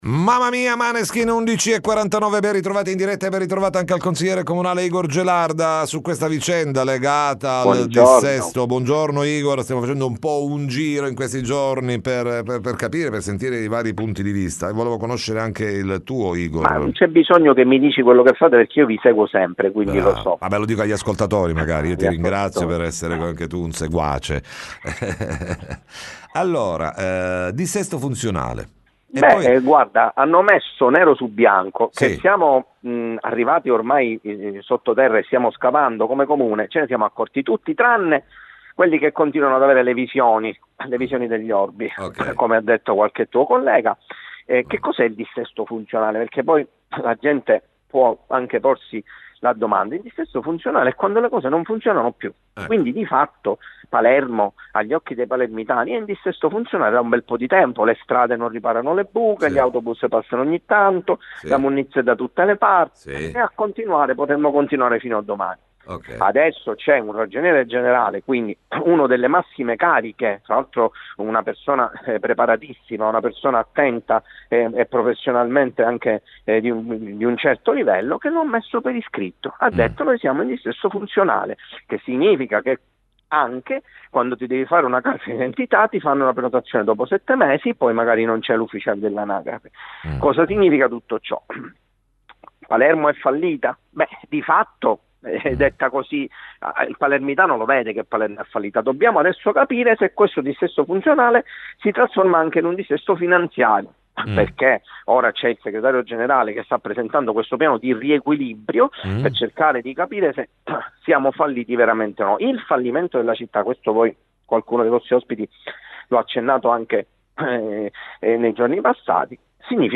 TM Intervista Igor Gelarda